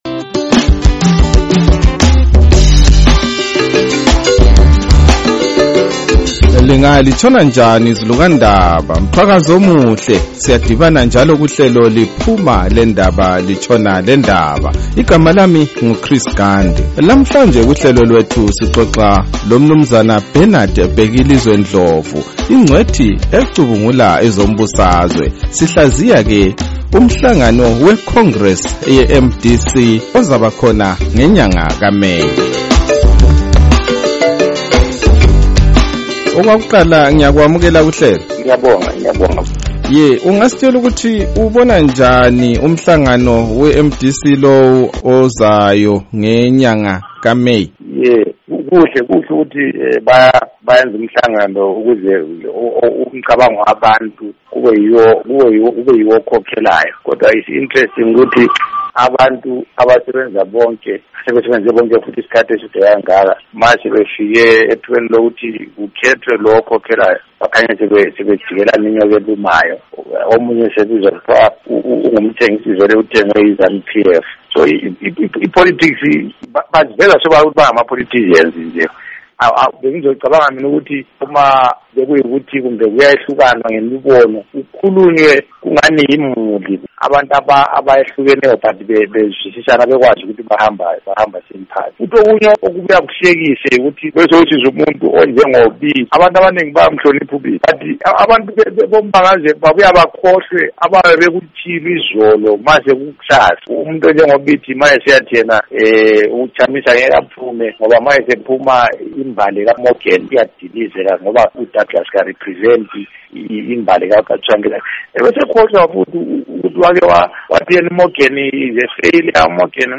Lalela Ingxoxo loMnu.